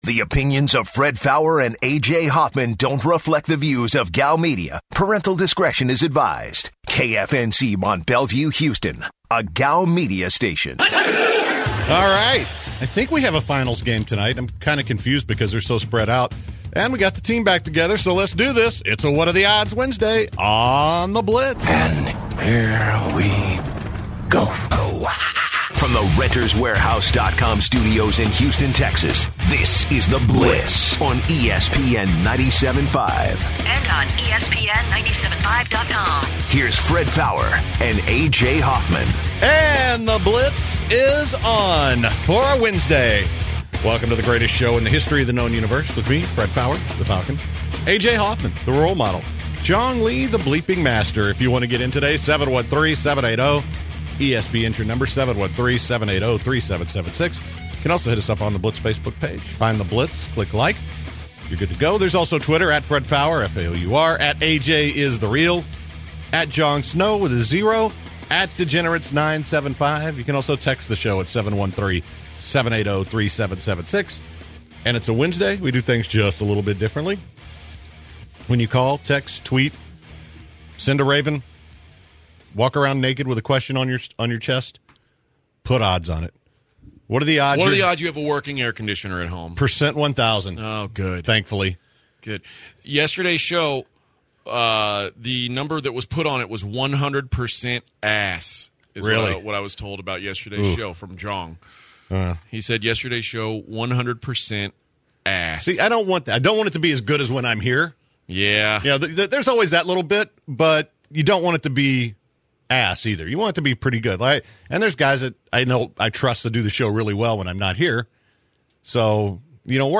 In hour 1, the crew discusses tipping at restaurants, Bob Stoops' recent retirement, and plays what are the odds with callers.